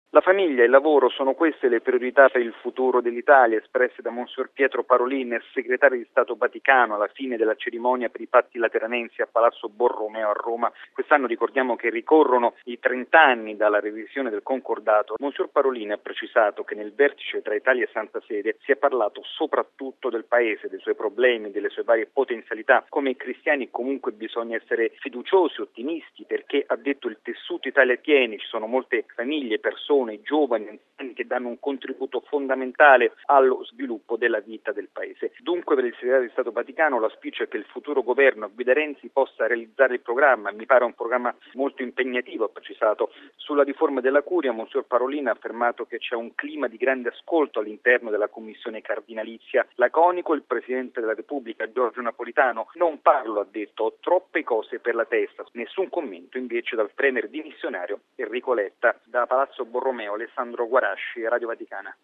Clima disteso alla cerimonia ieri pomeriggio a Roma, in ricordo dei Patti Lateranensi.